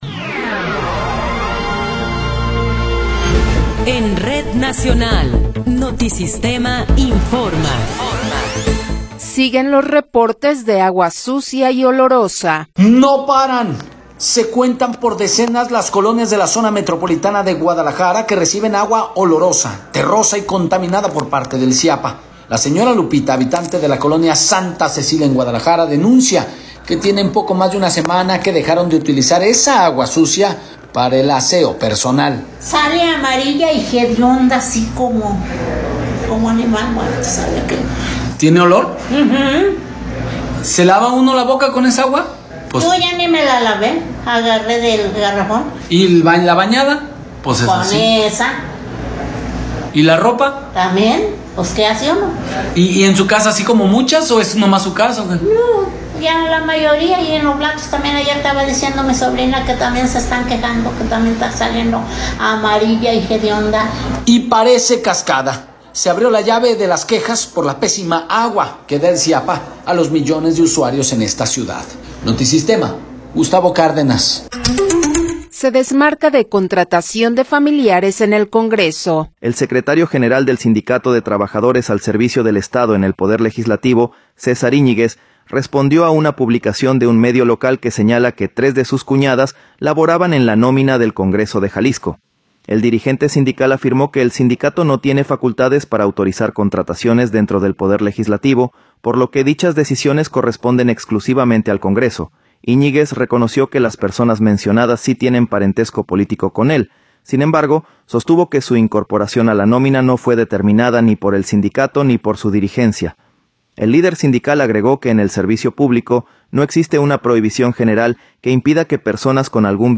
Noticiero 15 hrs. – 4 de Marzo de 2026 | Notisistema